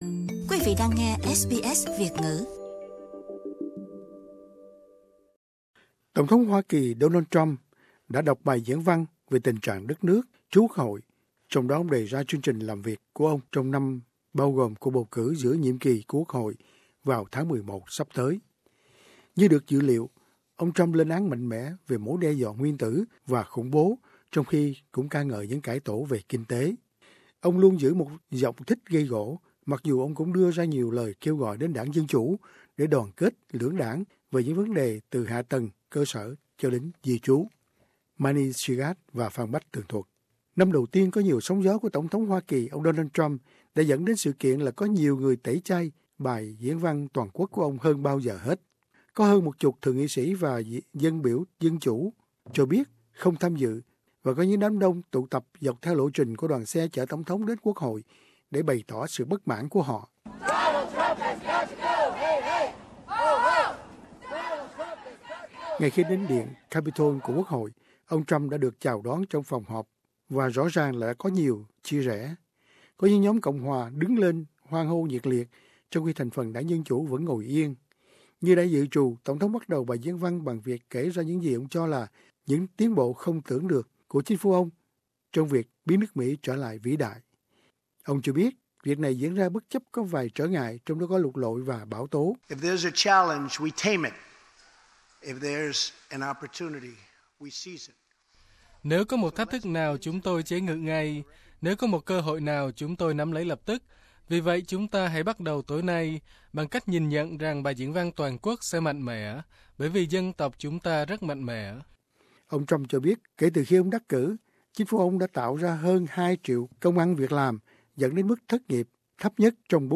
Donald Trump at his State of the Union address Source: AAP